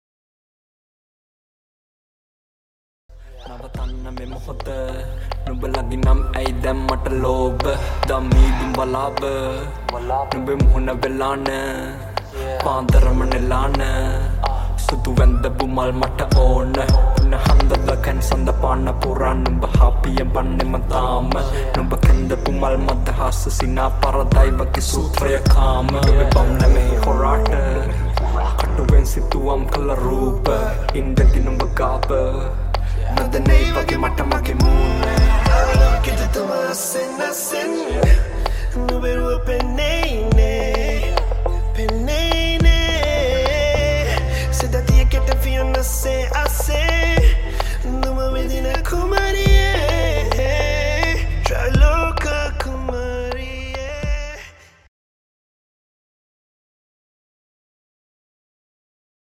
remix
Rap